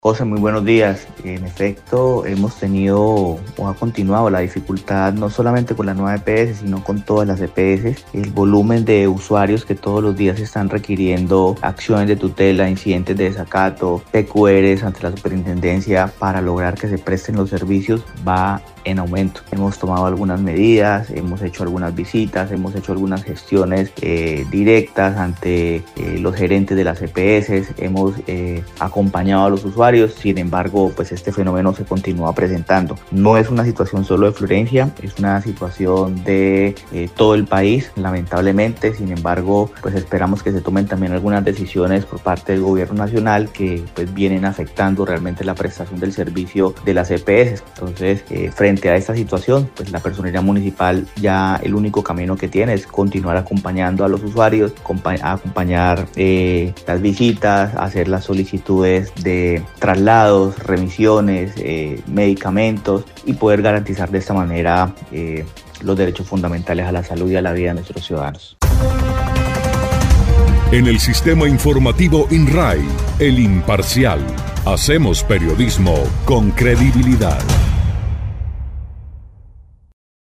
Jorge Luis Lara Andrade, personero del municipio de Florencia, explicó que, este ´fenómeno´ ha incrementado en todos los actores de la salud, contributivos y subsidiados, siendo ahora la más tutelada la Nueva EPS.
01_PERSONERO_JORGE_LARA_TUTELAS.mp3